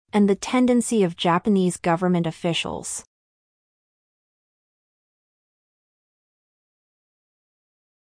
ここでは、弱音の機能語である定冠詞「theの聞き取りに注意しましょう。